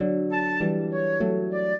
flute-harp
minuet11-10.wav